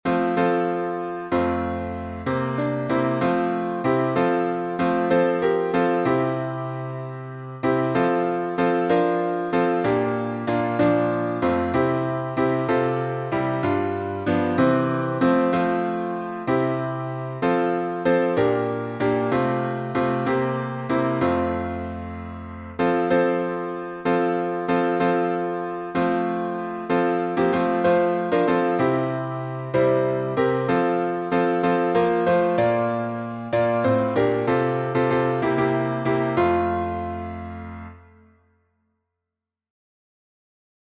We're Marching to Zion — F major — MARCHING TO ZION.